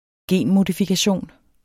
Udtale [ ˈgeˀn- ]